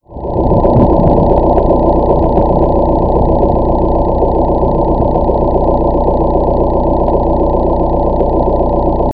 La inceputul anului la motor a aparut un zgomot cudat un fel de huruit, care am impresia ca sa mai intesificat pina acum.
Huruit filtrat